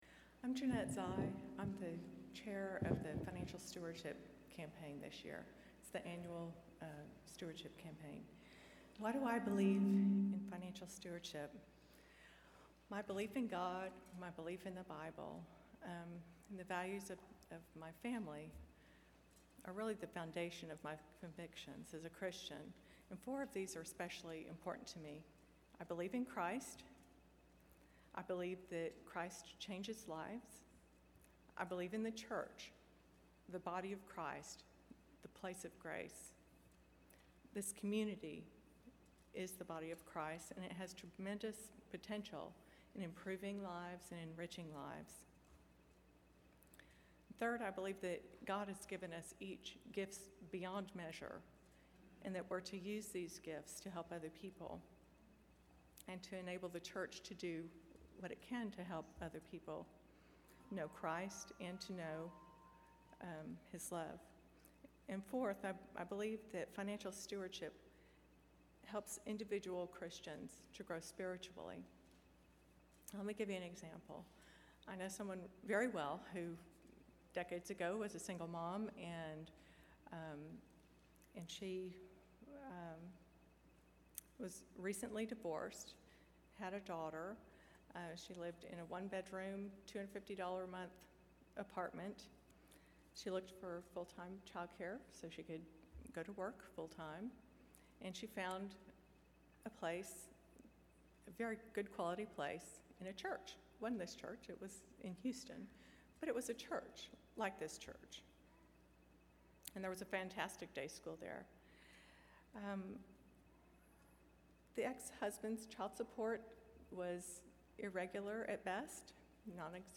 She was speaking on behalf of our annual stewardship campaign. She completely surprised most of us with her personal story of transformation from a struggling single mom to corporate success story.